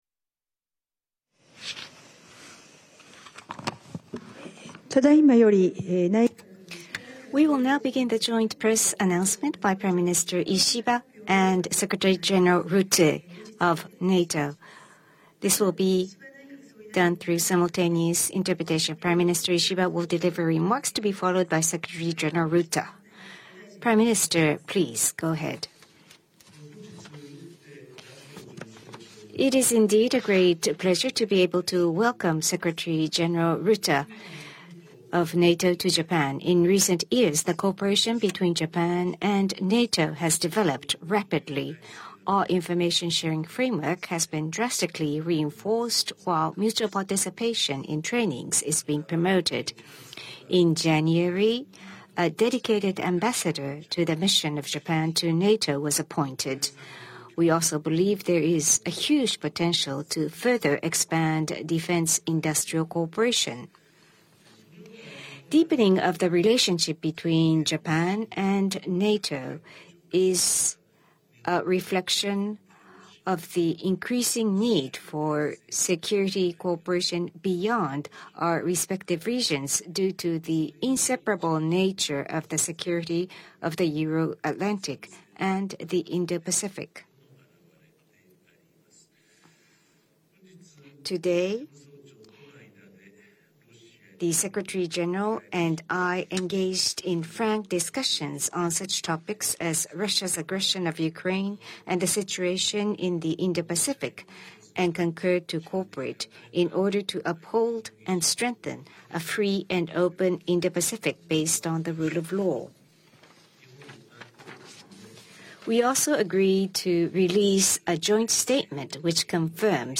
Remarks by NATO Secretary General Mark Rutte with the Prime Minister of Japan, Shigeru Ishiba Go to detail page